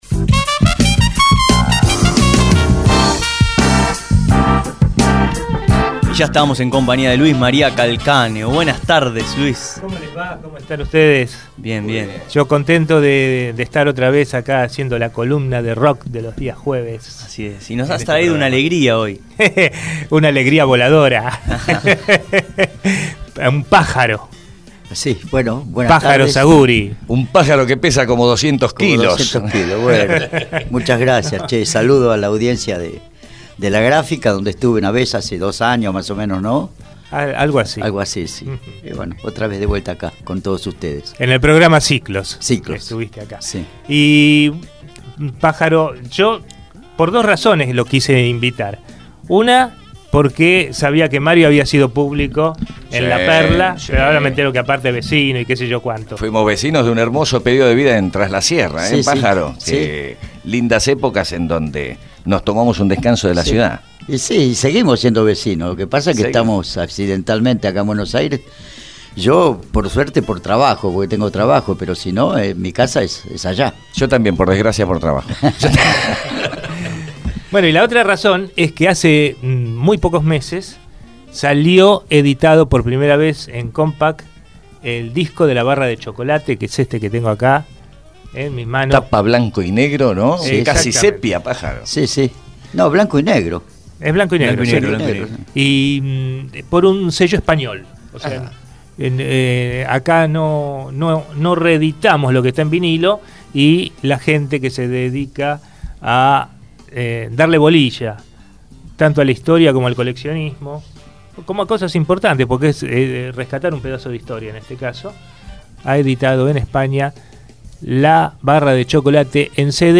Alberto Ramón García, más conocido como «Pajarito Zaguri», estuvo en Abramos la Boca.
Todos los jueves, en la tarde de la gráfica, se realiza la columna de ROCK.